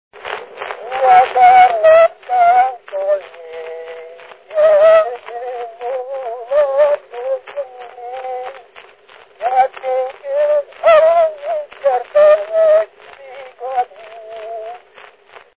Felföld - Nyitra vm. - Pográny
ének
Stílus: 8. Újszerű kisambitusú dallamok
Szótagszám: 6.6.6.6
Kadencia: V (2) 4 1